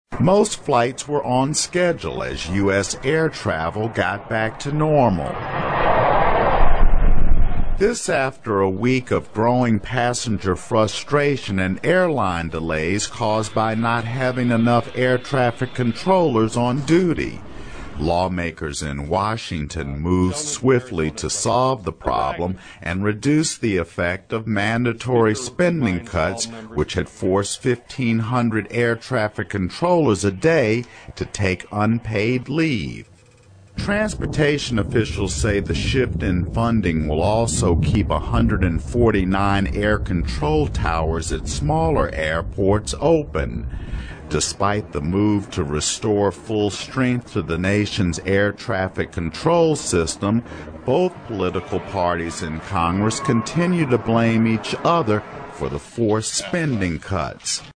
Questions 1 and 2 will be based on the following news item.